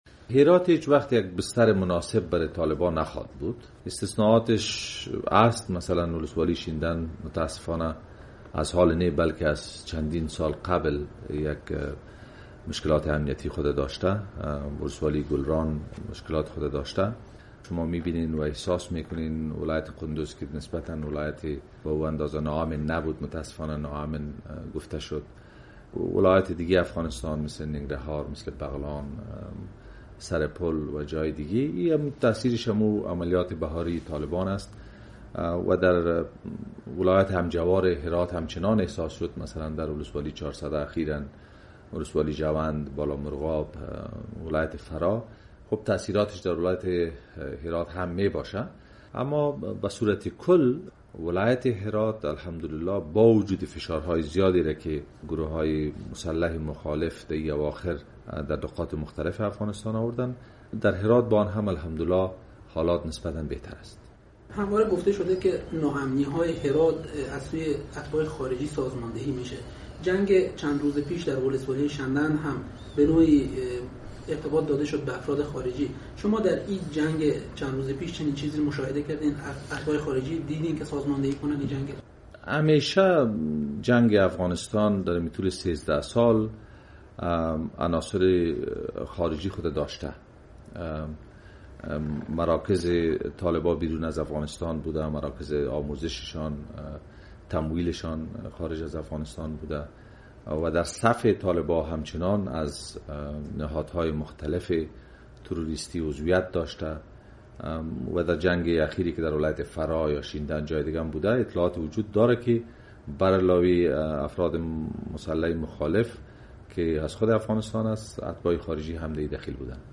مصاحبه با آصف رحیمی، والی هرات